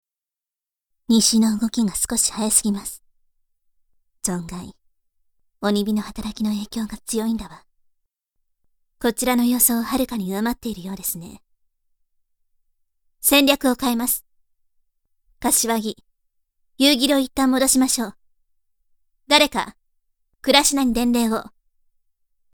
【サンプルセリフ】
（戦場を見据え、冷静に戦況を判断）
そこに、透明感のあるお声のイメージもプラスして、凛とした雰囲気で設定してみました。